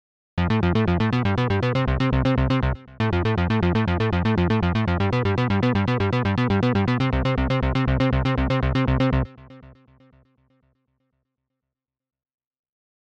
Category 🎮 Gaming
game gaming level power sifi synth up video sound effect free sound royalty free Gaming